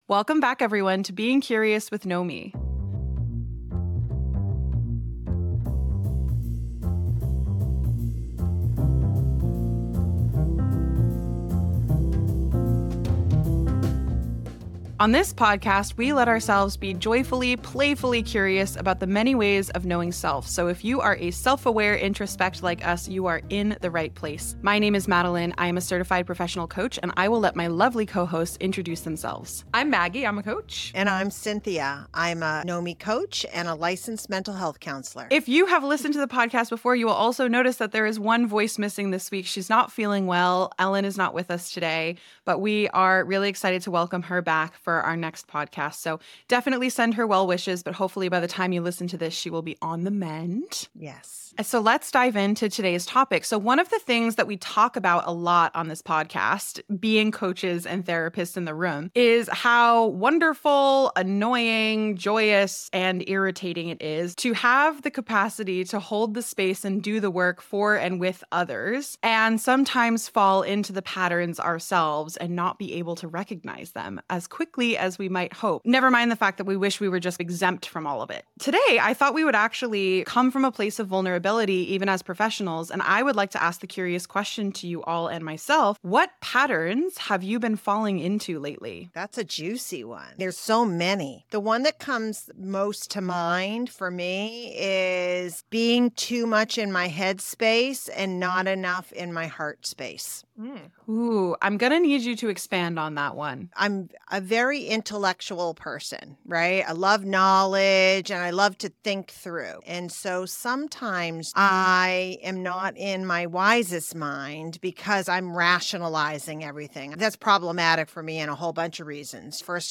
In this episode of Being Curious with Knomii, we dive into the frustrating experience of feeling trapped in our own heads. Coaches and therapists